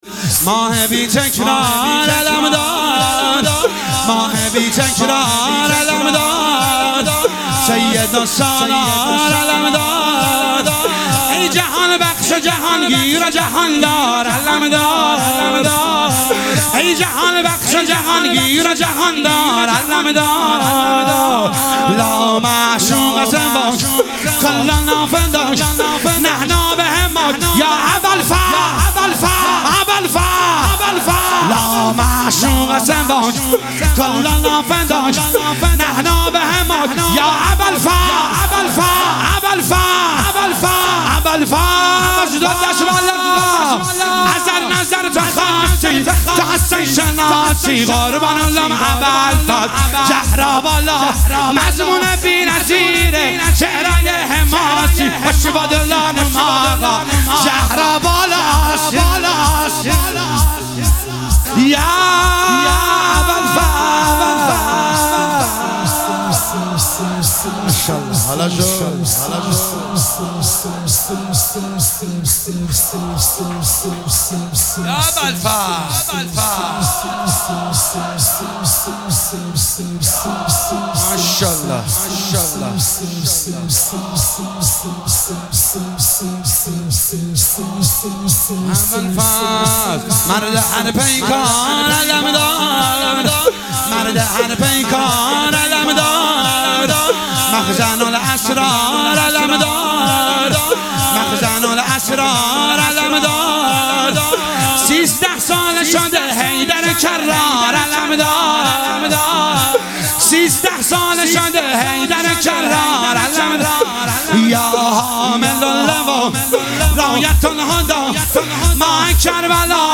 مداحی شور شب ششم محرم 1445